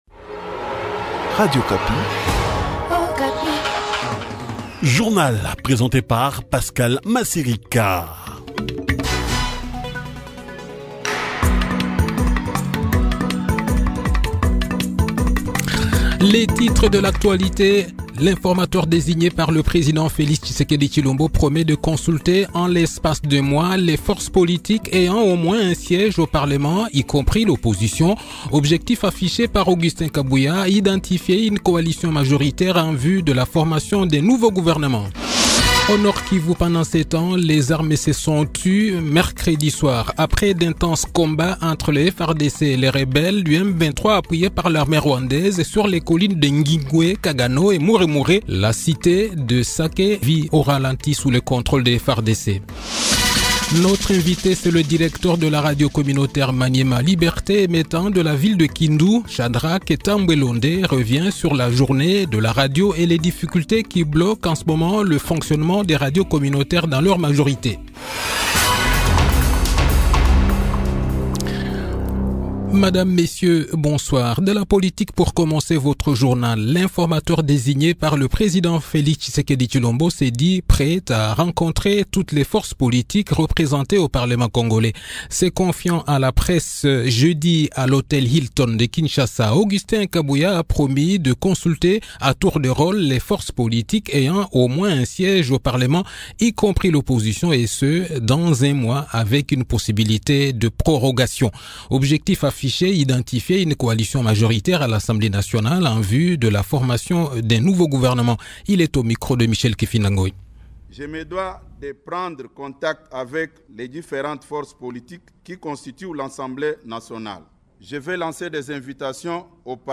Journal Soir
Le journal de 18 h, 15 février 2024